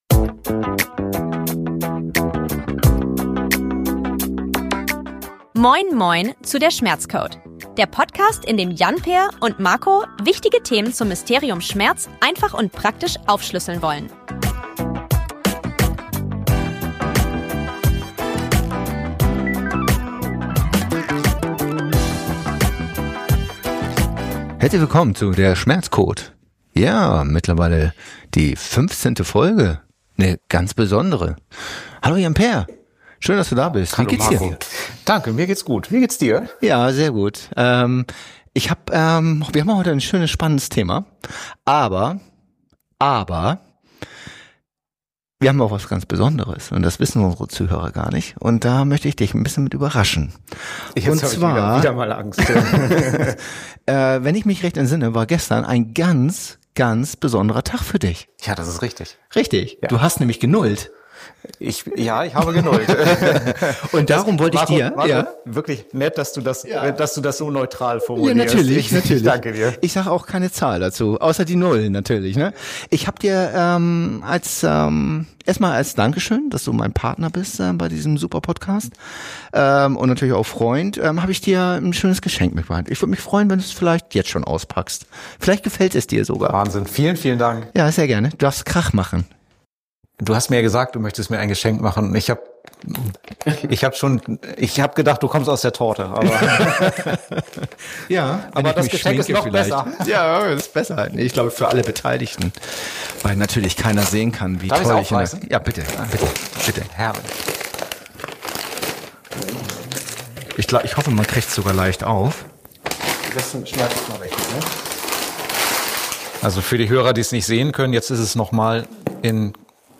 ein aufschlussreiches Gespräch